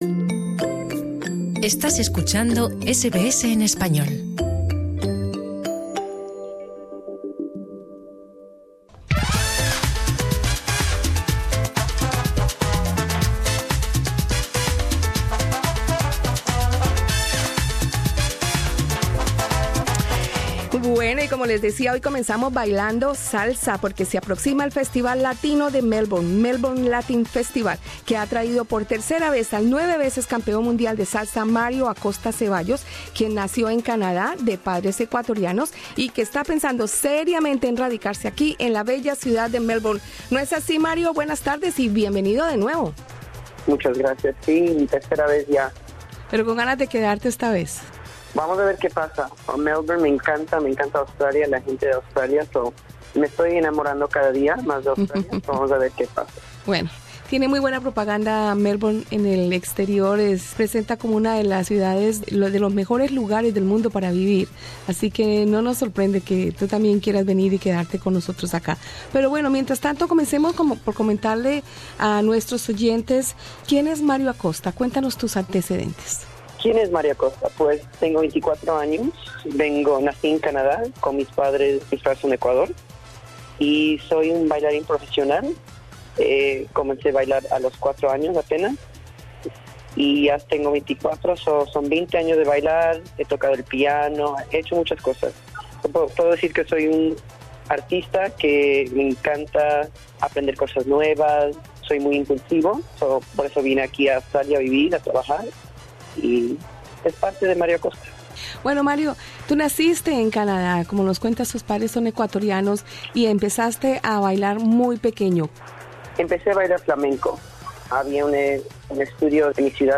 Escucha arriba en nuestro podcast la entrevista.